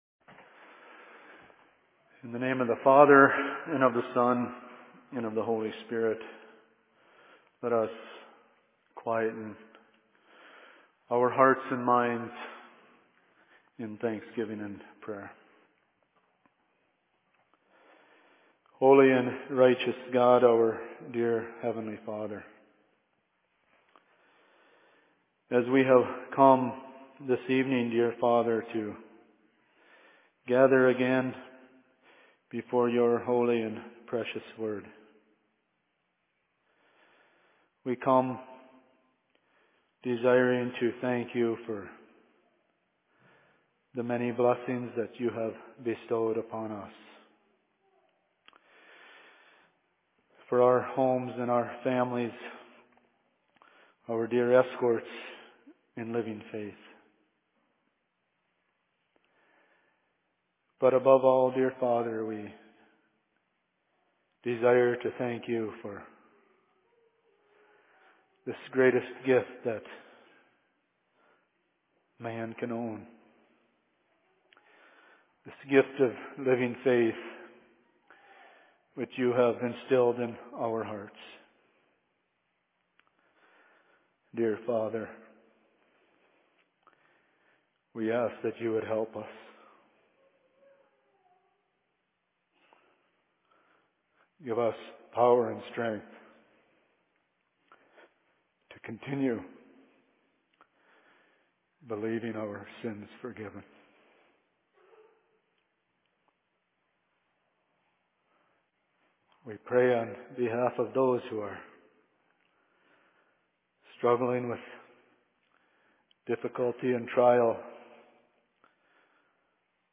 Sermon in Cokato 17.02.2013
Location: LLC Cokato